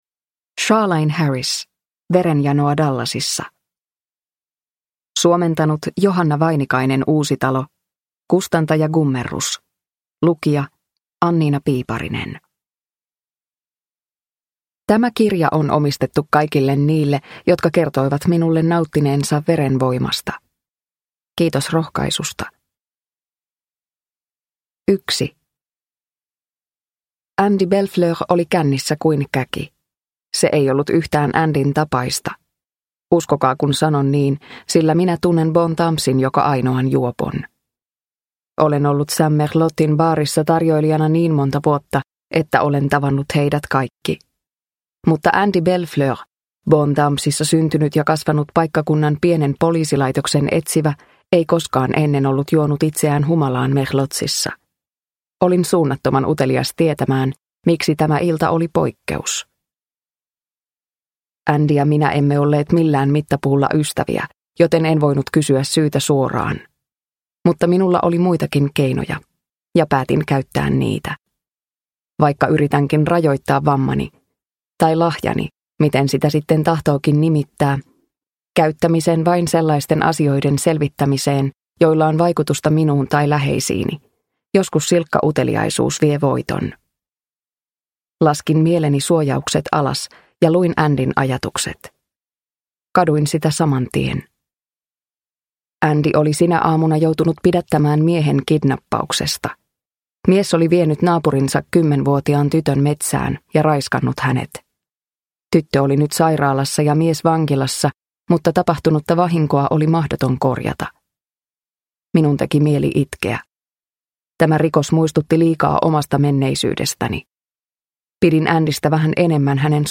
Verenjanoa Dallasissa – Ljudbok – Laddas ner